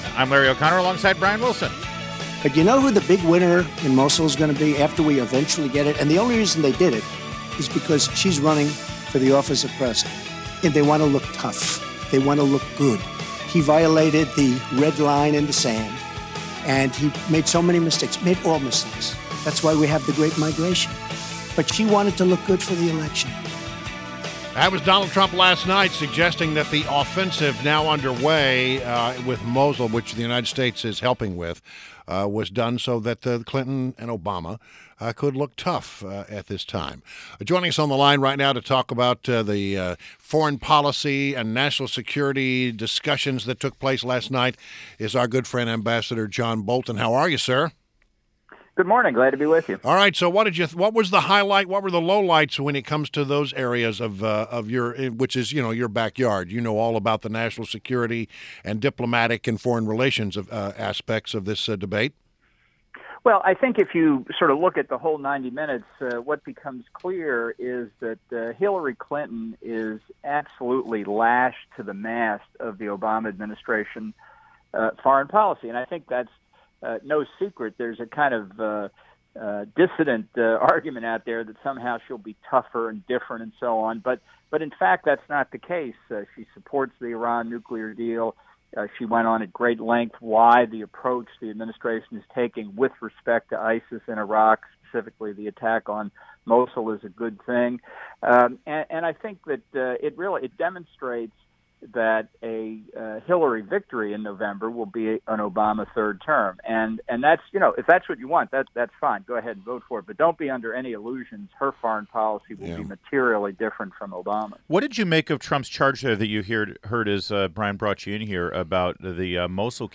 INTERVIEW – AMB JOHN BOLTON – Former UN AMBASSADOR — discussed the presidential debate’s handling of foreign affairs including Iran, Mosul and Russia.